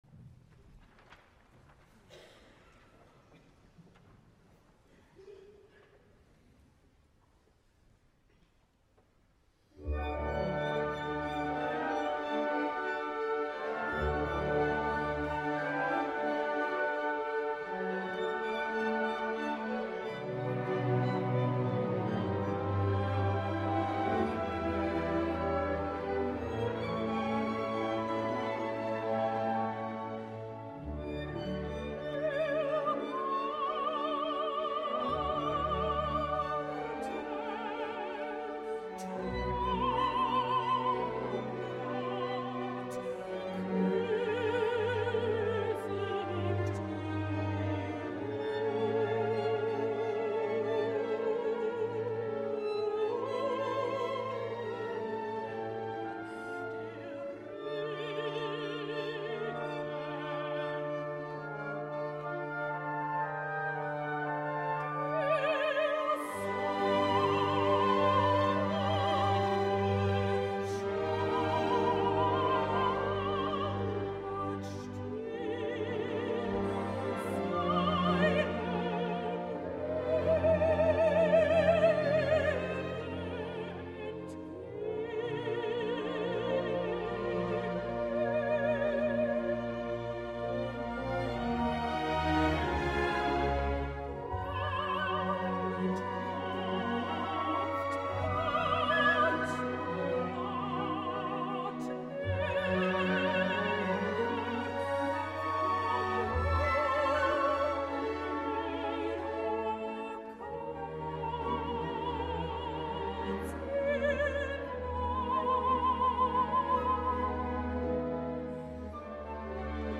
El passat 4 d’octubre a la Sala Pleyel de Paris, va tenir lloc un concert de l’Orchestre de Paris sota la direcció de Tomas Netopil en el que per finalitzar la primera part va intervenir la soprano Anja Harteros per cantar els Vier letzte Lieder de Richard Strauss.
No cal dir que la soprano alemanya està fantàstica i sembla que feliçment recuperada d’una malaltia que l’ha fet cancel·lar moltes actuacions darrerament.
Aquí us deixo amb la carnosa veu d’Harteros, acompanyada per el cada vegada més anomenat Tomas Netopil, en aquests lied tan tardorals, suggeridors i decadents, bellíssims fins el plor.
Tomas Netopil i Anja Harteros a la Sala Pleyel de Paris el 4 d’octubre de 2012